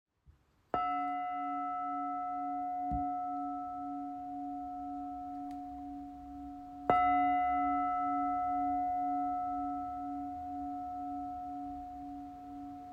Nepalese Engraved Bowl · 16 cm
Magnificent engraved Nepalese bowl, supplied with its cushion and mallet.
They are made of 7 metal alloys according to ISO 9001:2015 standards ICP-OES analysis